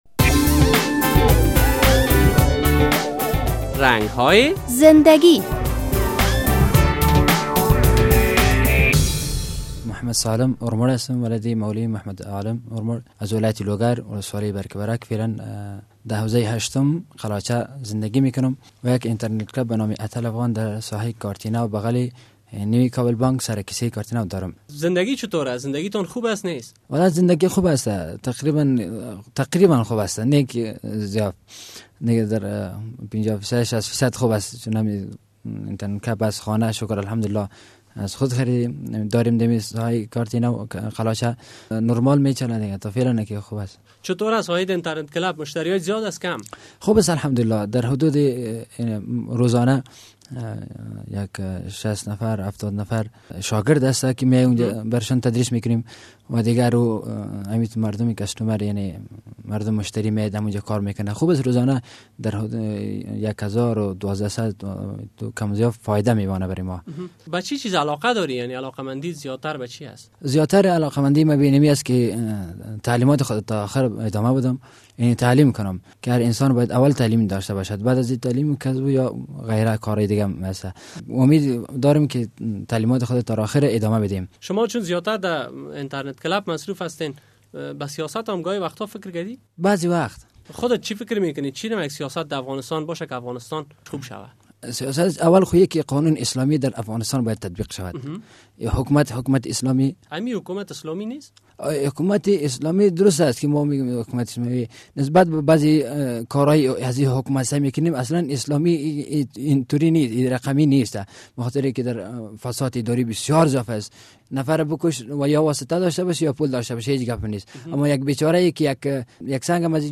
در یک دهه اخیر افغان ها با تکنالوژی جدید آشنایی بیشتر پیدا کرده اند، پدیده های جدید مثل ظهور موبایل، انترنت و سهولت های جدید دیگر زنده گی افغان ها را تغییر داده است. در این برنامه با یک تن از کسانی که صاحب یک انترنت کلپ است مصاحبه کرده ایم: